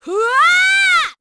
Seria-Vox_Casting1_kr.wav